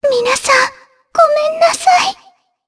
Shea-Vox_Dead_jp_b.wav